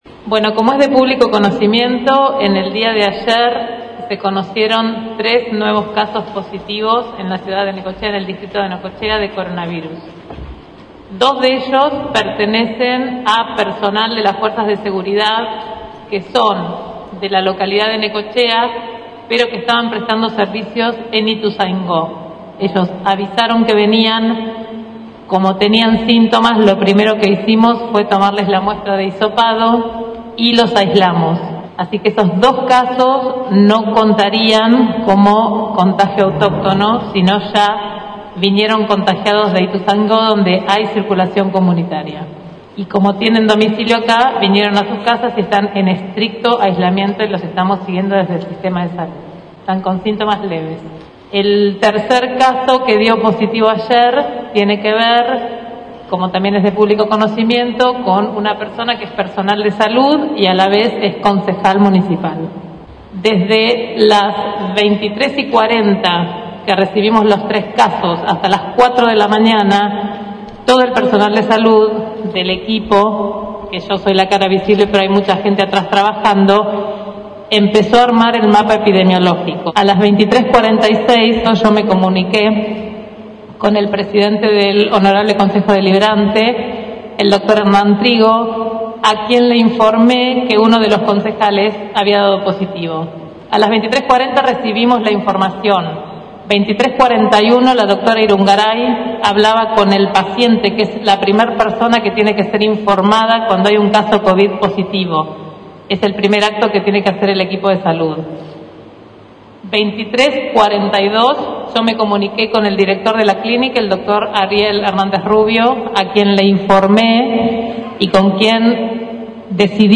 La secretaria de Salud, Ruth Kalle, respondió en rueda de prensa encabezada por el intendente Arturo Rojas, y de manera pormenorizada, el documento que hicieron circular esta tarde concejales de la oposición.